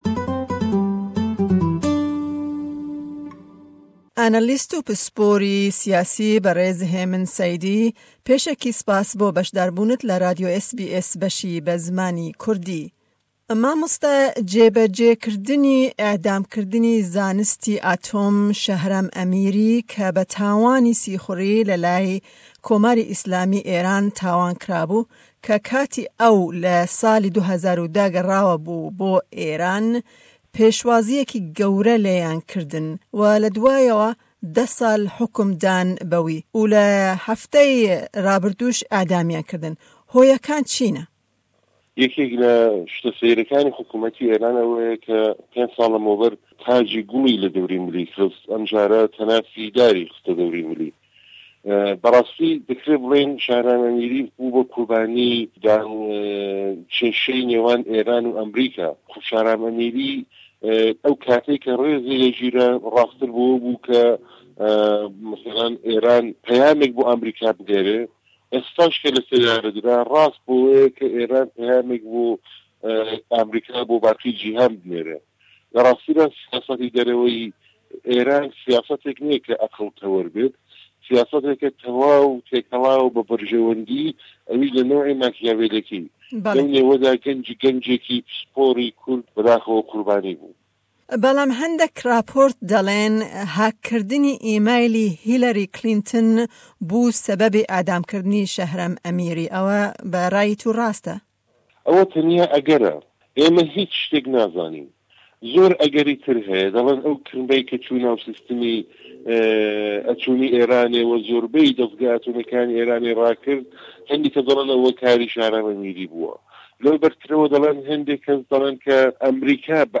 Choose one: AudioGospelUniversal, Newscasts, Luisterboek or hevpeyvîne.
hevpeyvîne